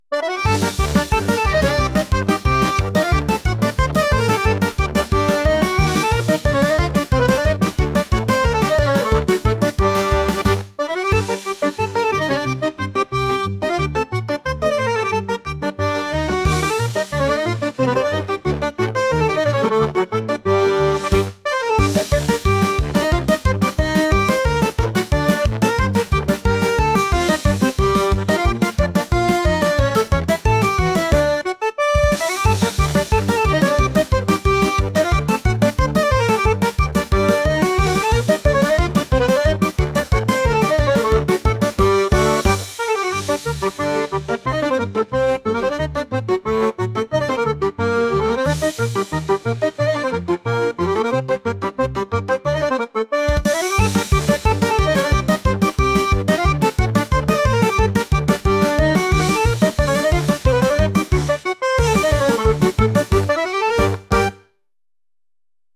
急いでいるようなハイテンポなアコーディオン曲です。